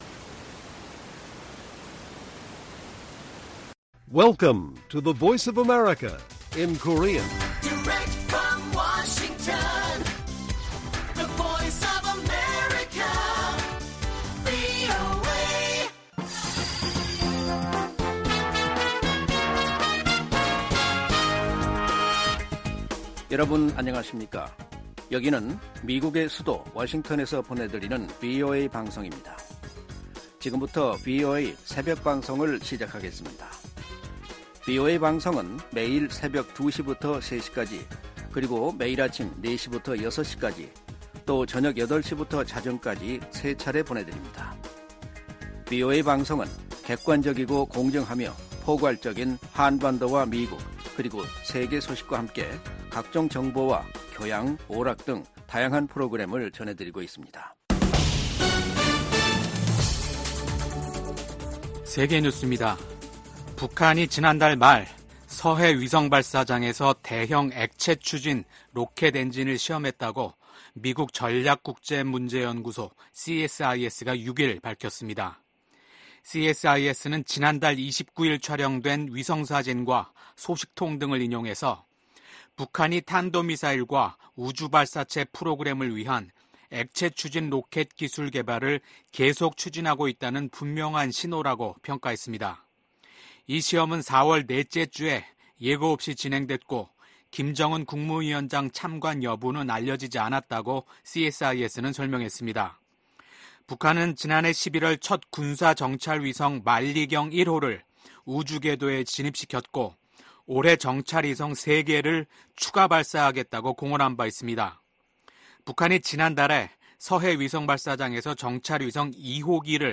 VOA 한국어 '출발 뉴스 쇼', 2024년 5월 9일 방송입니다. 도널드 트럼프 전 대통령은 자신이 대통령에 당선되면 한국이 주한미군 주둔 비용을 더 많이 부담하지 않을 경우 주한미군을 철수할 수 있음을 시사했습니다. 러시아 회사가 수천 톤에 달하는 유류를 북한으로 운송할 유조선을 찾는다는 공고문을 냈습니다.